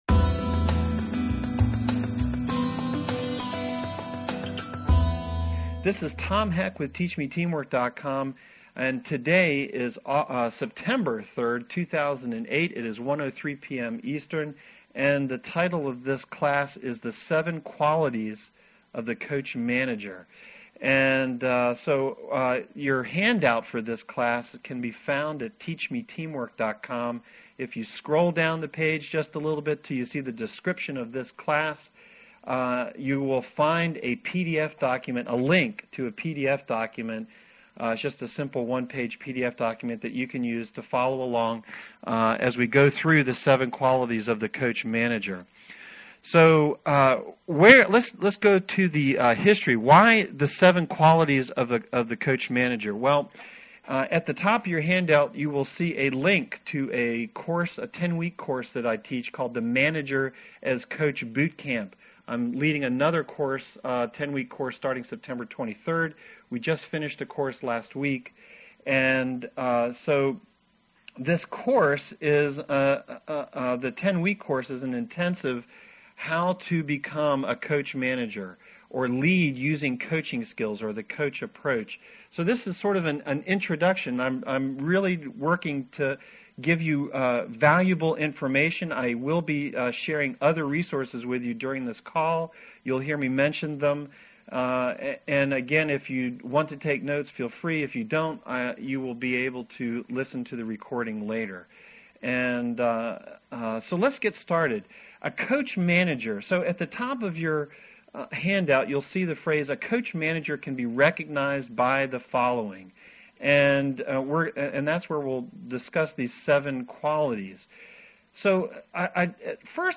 Participants were able to ask questions and get specific answers to their management questions.